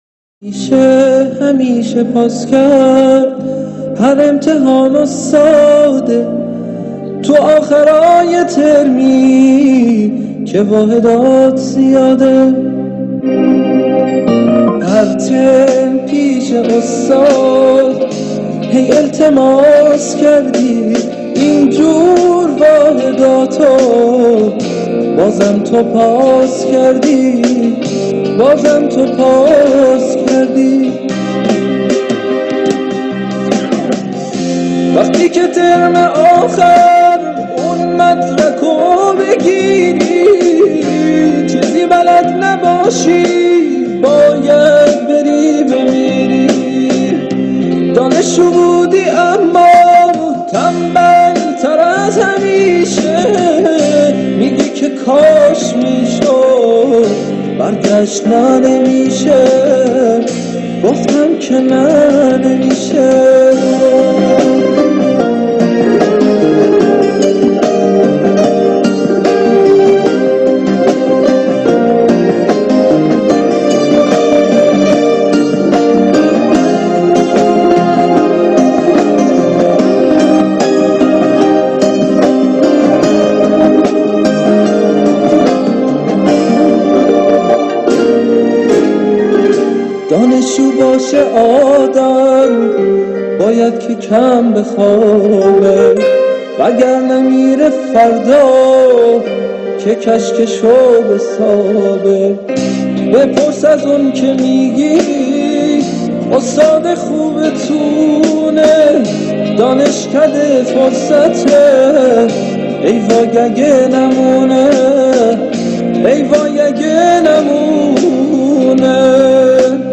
1 آخرین مطالب موسیقی موسیقی پاپ